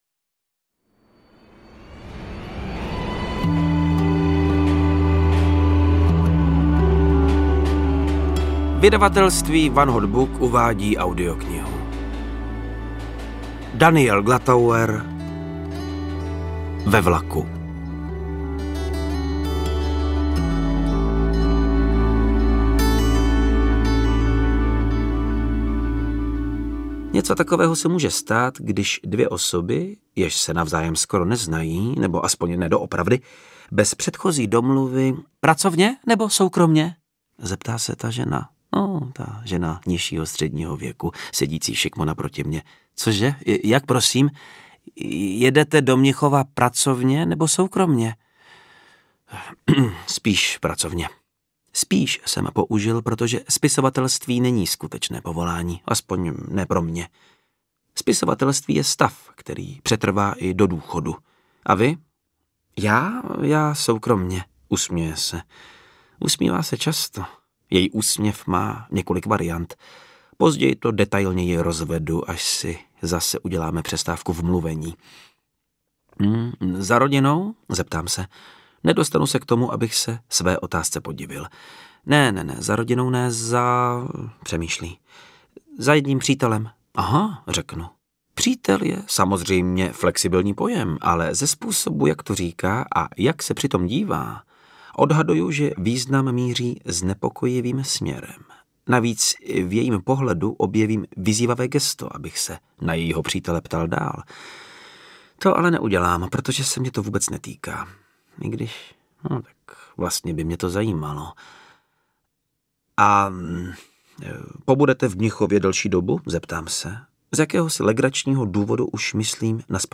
Audiobook
Read: Václav Neužil